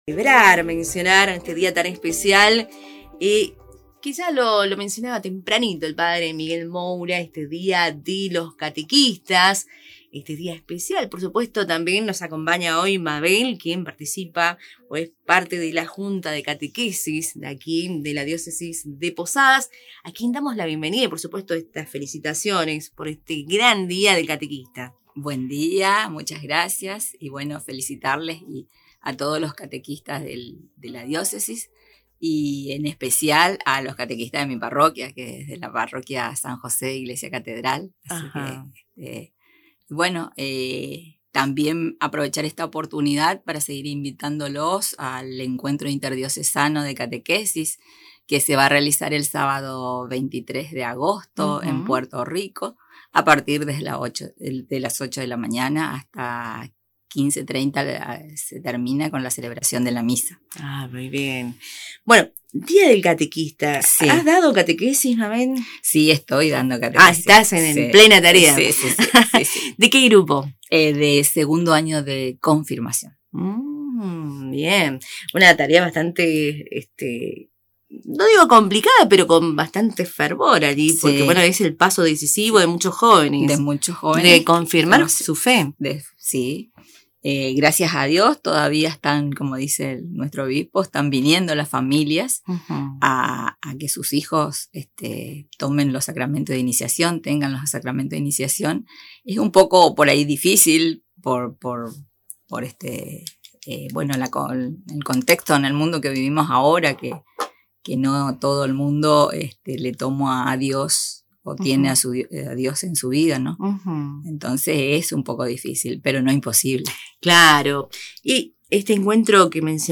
Durante una entrevista en Radio Tupambaé se reiteró la invitación al Encuentro Interdiocesano de Catequesis, que se desarrollará el sábado 23 de agosto en la localidad de Puerto Rico, desde las 8 hasta las 15:30, con la celebración de la misa de clausura.